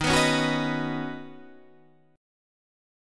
Ebm7#5 chord